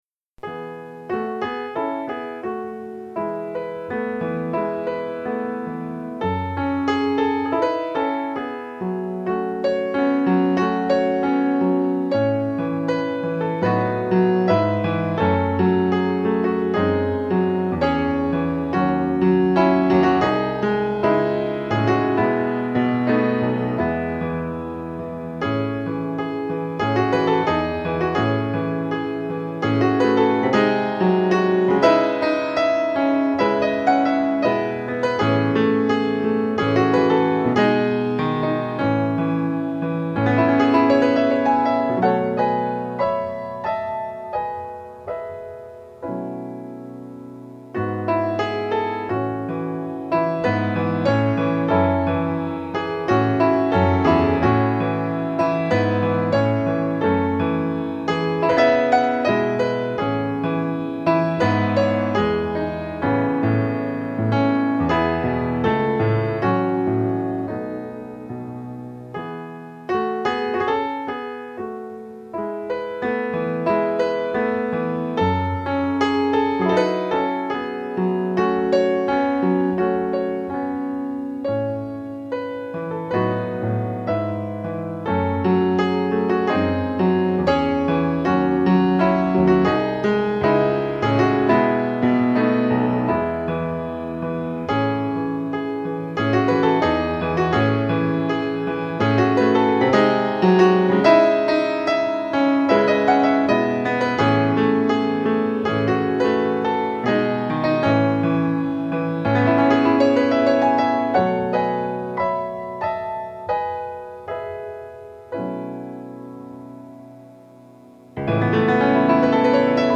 Klavierstücke
gespielt auf einem Feurich 197